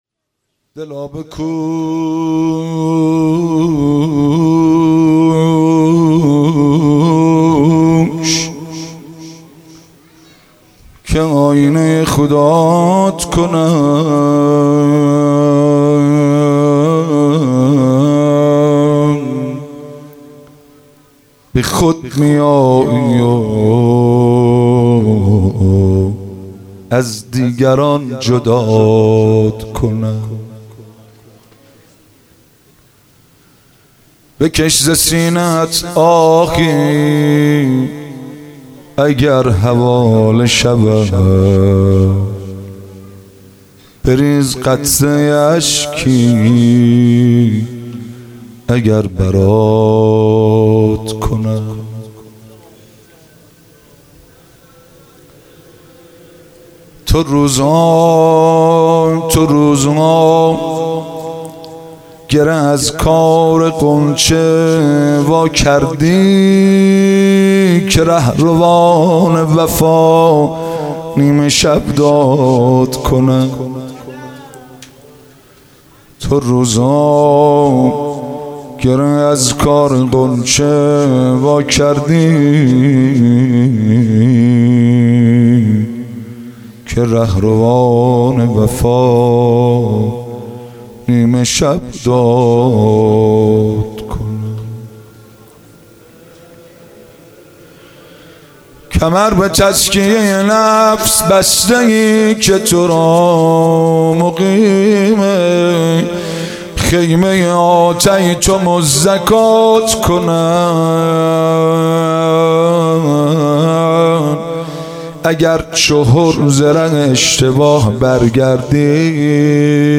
مناسبت : شب نوزدهم رمضان - شب قدر اول
قالب : مناجات